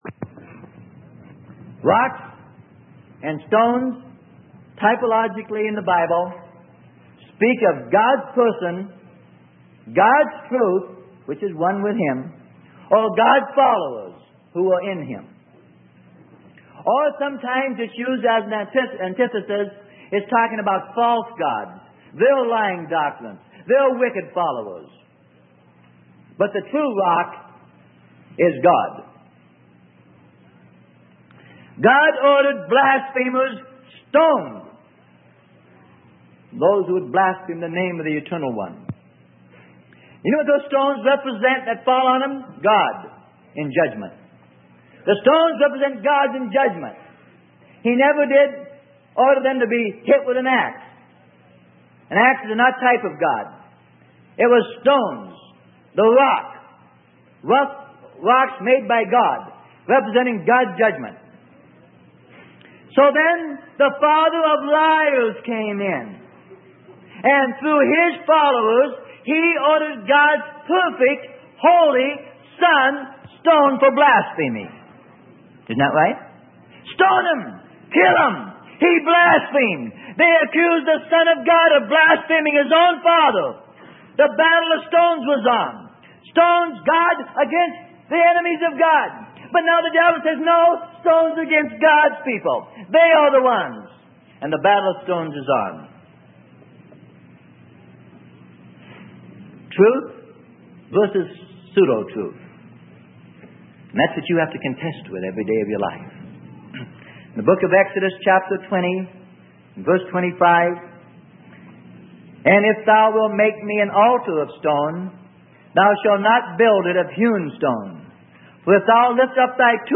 Sermon: The Battle of Stones - Freely Given Online Library